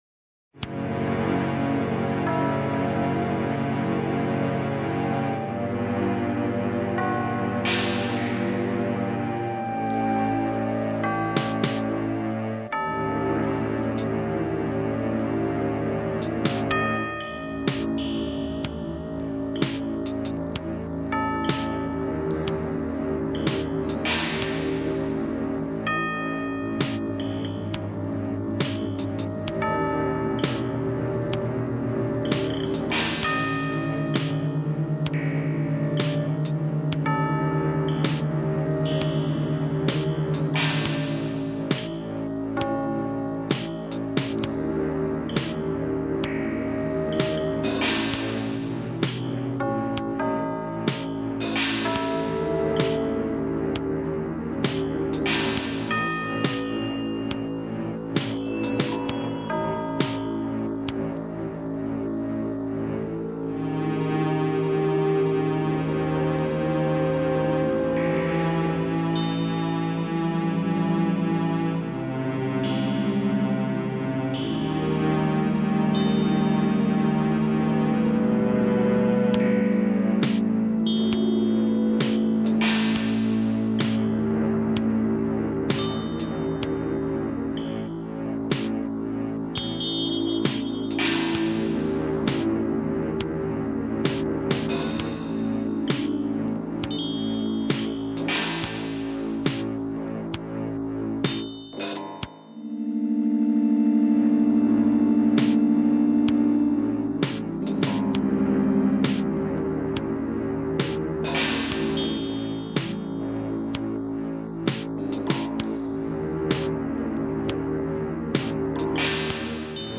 BGM Music